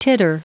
Prononciation du mot titter en anglais (fichier audio)
Prononciation du mot : titter